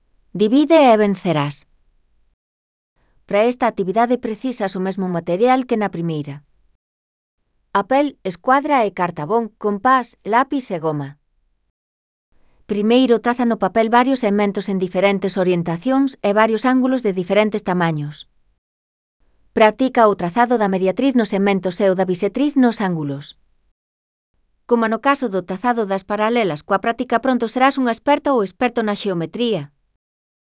Elaboración propia (proxecto cREAgal) con apoio de IA, voz sintética xerada co modelo Celtia.